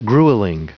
Prononciation du mot grueling en anglais (fichier audio)
Prononciation du mot : grueling